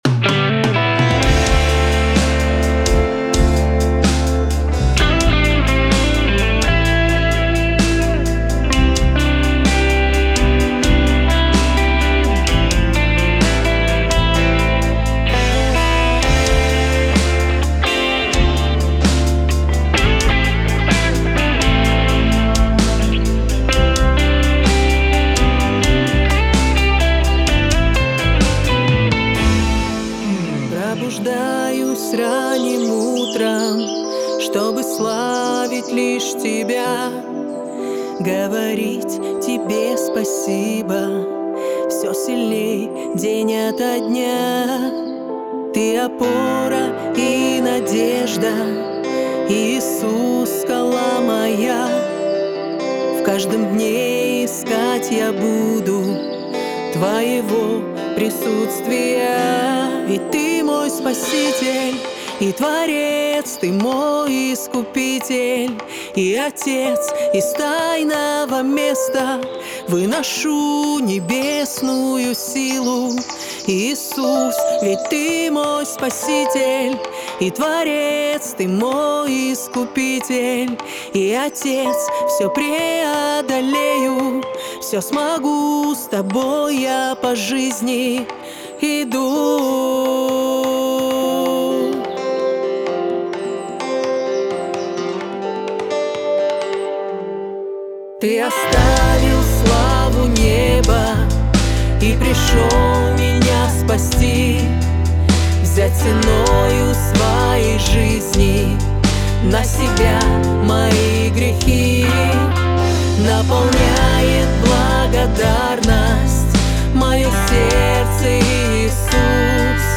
163 просмотра 219 прослушиваний 12 скачиваний BPM: 128